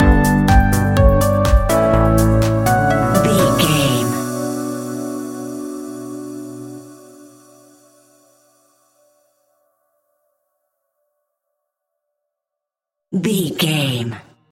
Aeolian/Minor
groovy
uplifting
futuristic
driving
energetic
drum machine
synthesiser
house
electro
funky house
instrumentals
synth leads
synth bass